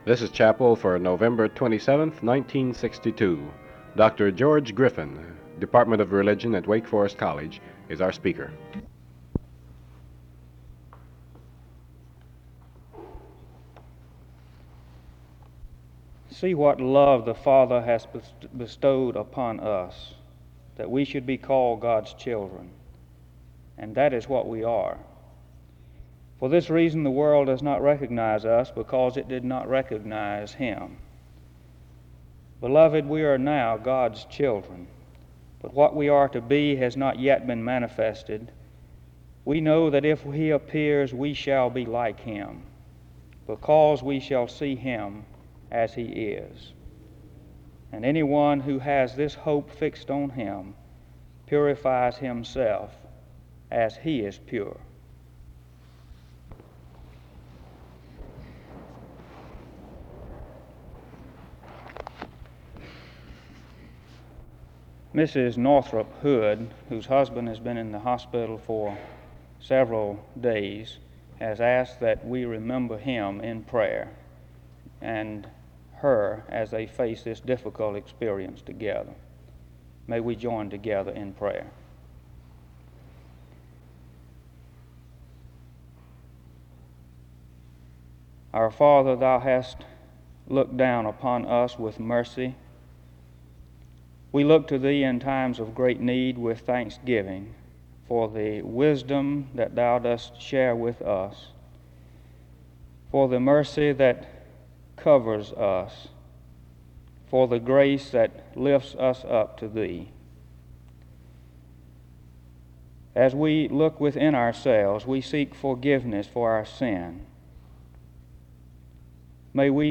SEBTS Chapel
The service begins with a scripture reading and prayer from 0:15-4:23.
SEBTS Chapel and Special Event Recordings SEBTS Chapel and Special Event Recordings